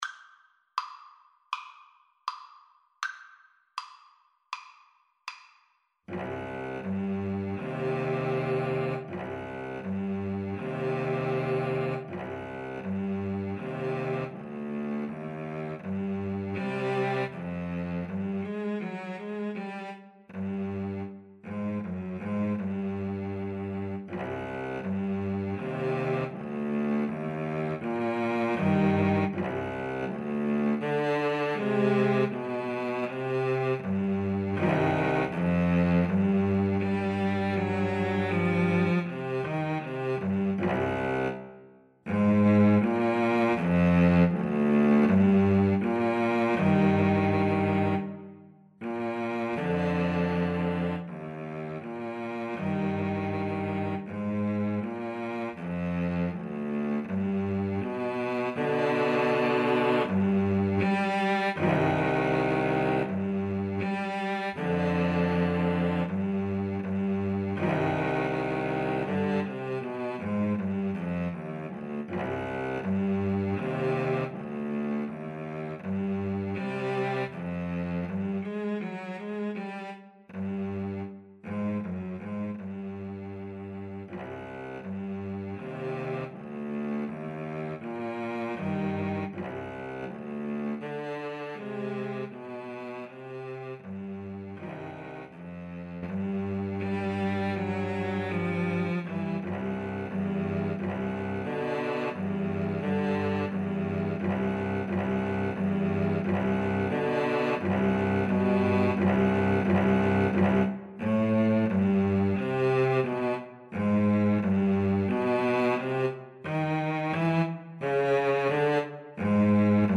Free Sheet music for Cello Duet
C minor (Sounding Pitch) (View more C minor Music for Cello Duet )
Andante cantabile
4/4 (View more 4/4 Music)
Cello Duet  (View more Intermediate Cello Duet Music)
Classical (View more Classical Cello Duet Music)